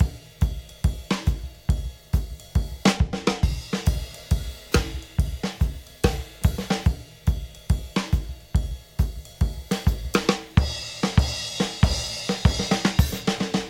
描述：有摇摆鼓，但没有摇摆鼓的类型，所以我把它放在爵士乐里。
标签： 140 bpm Jazz Loops Drum Loops 2.31 MB wav Key : Unknown
声道立体声